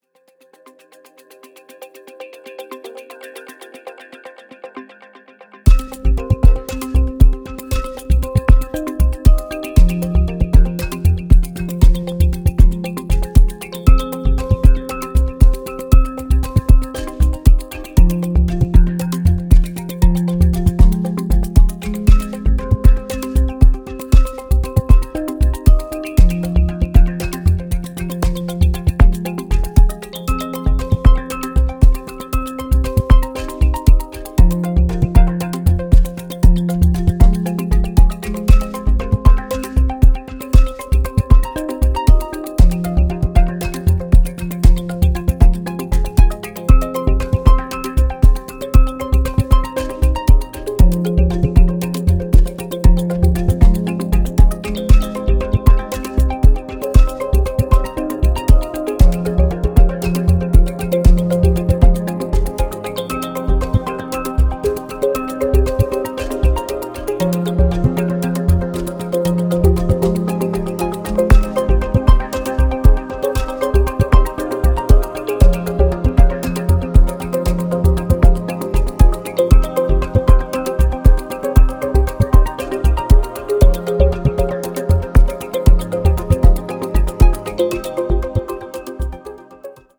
captures electro's most playful side